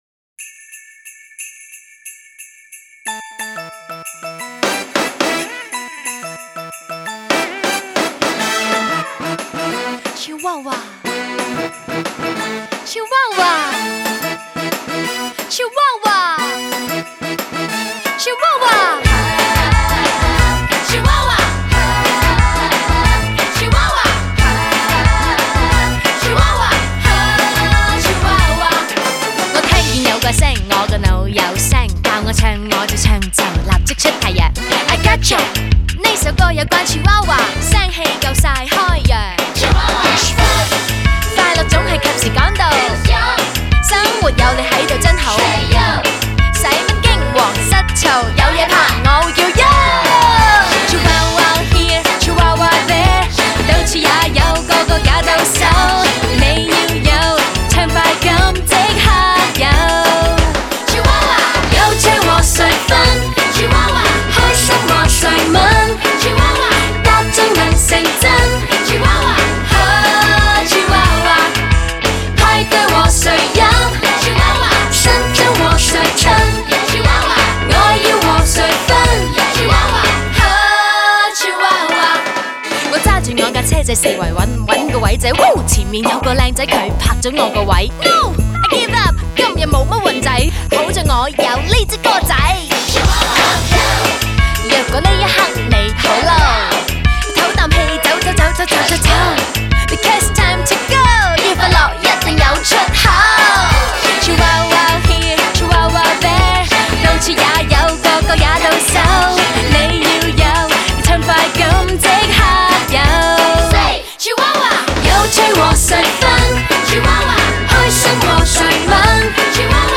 此单曲中节奏强劲，